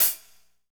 HAT P C L0MR.wav